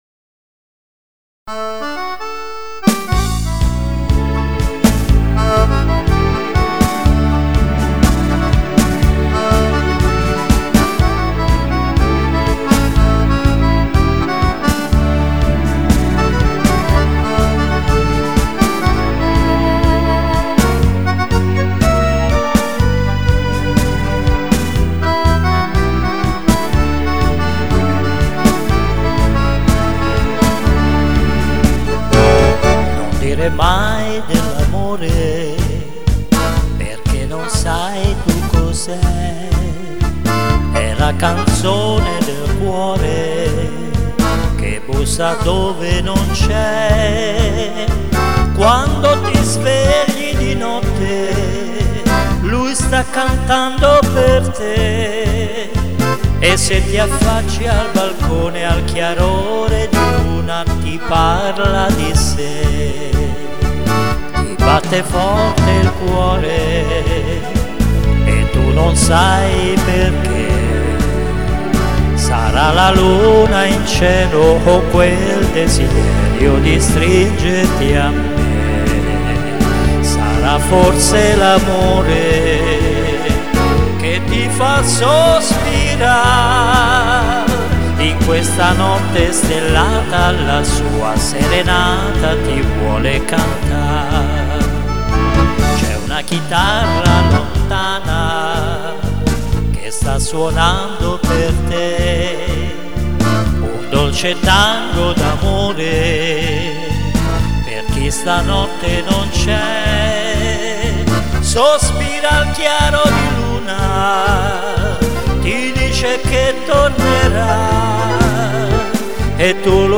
Canzoni e musiche da ballo
tango